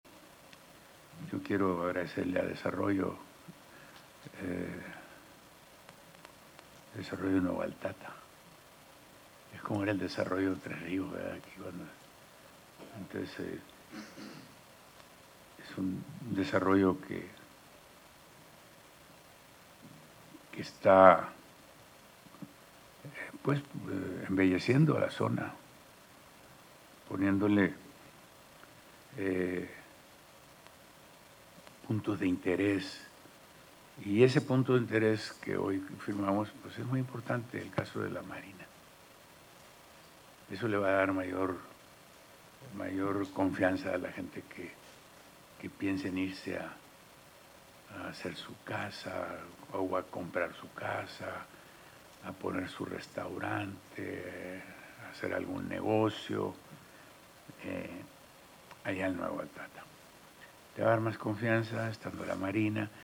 Audio-de-gobernador-.mp3